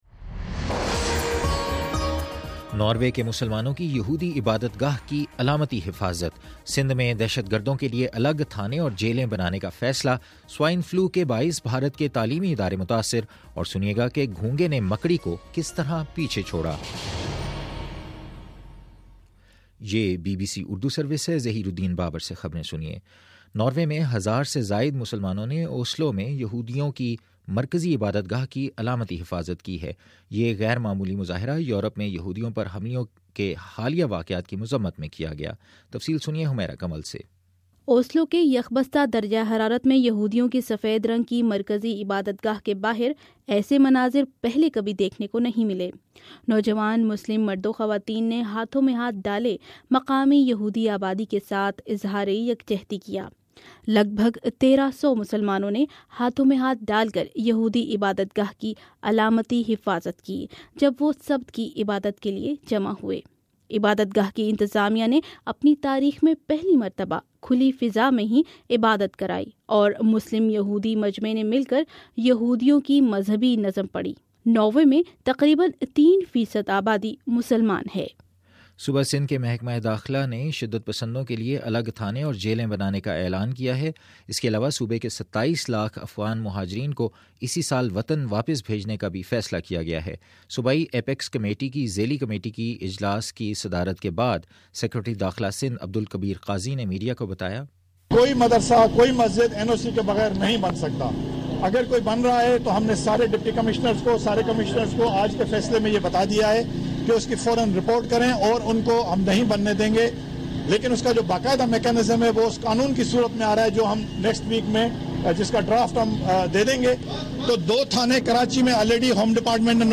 فروری 22 : صبح نو بجے کا نیوز بُلیٹن